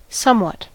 somewhat: Wikimedia Commons US English Pronunciations
En-us-somewhat.WAV